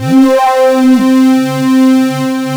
OSCAR 10 C4.wav